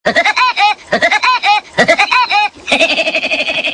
Categoría Graciosos